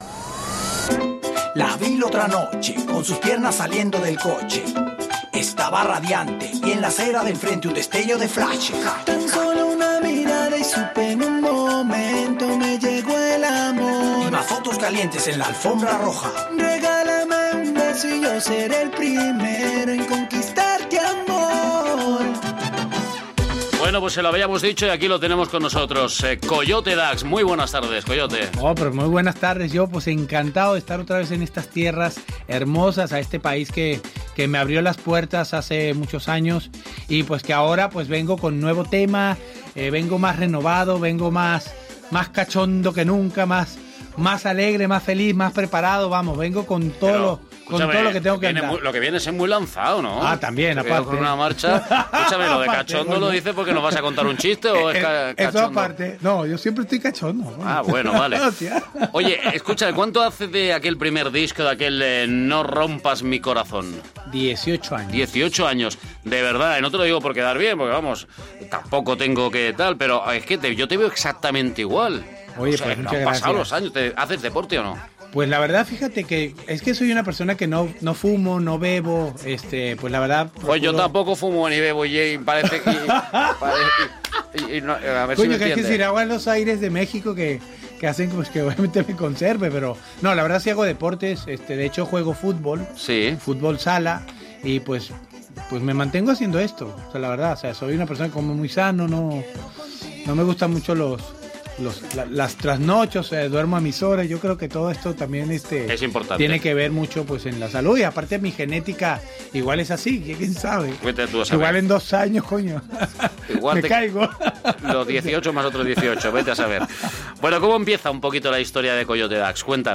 Entrevista a Coyote Dax
entrevista-coyote-dax.mp3